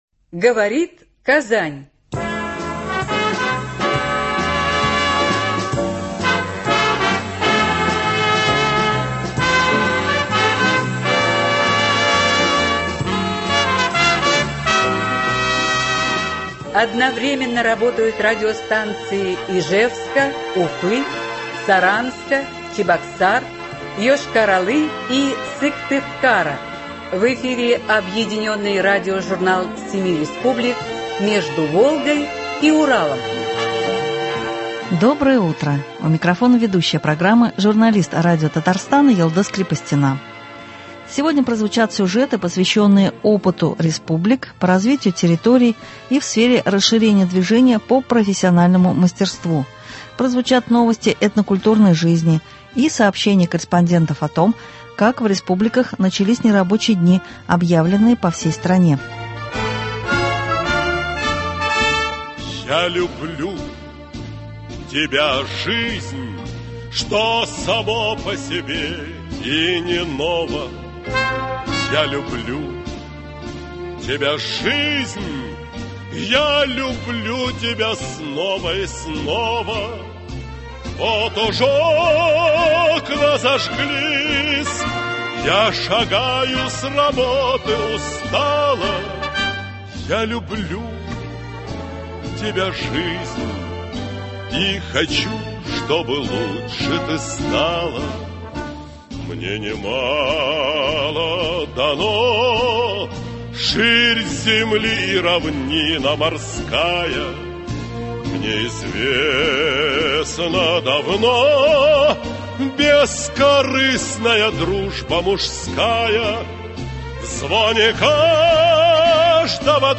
Сегодня прозвучат сюжеты, посвященные опыту республик по развитию территорий и расширению движения по профессиональному мастерству, прозвучат новости этнокультурной жизни и сообщения корреспондентов о том, как в республиках начались нерабочие дни, объявленные по всей стране.